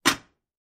fo_fryingpan_lid_01_hpx
Frying pans are slammed together. Slam, Frying Pan Rattle, Frying Pan Lid, Frying Pan